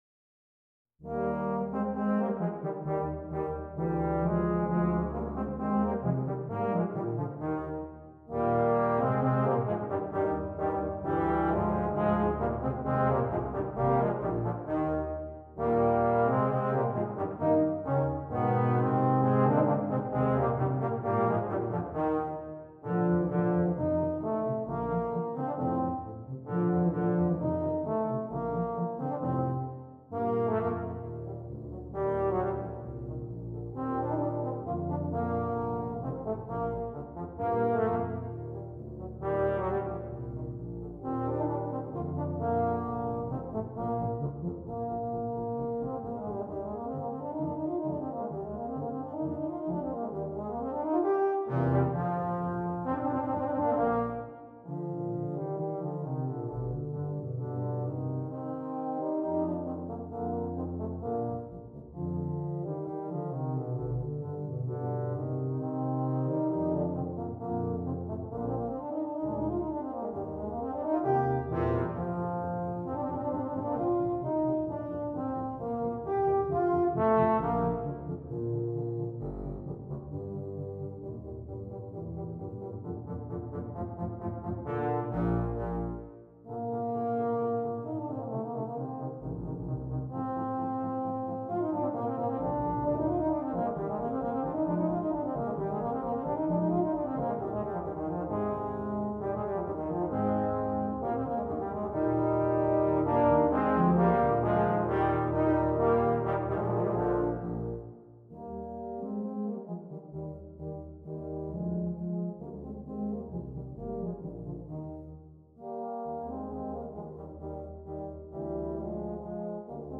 2 Euphoniums, 2 Tubas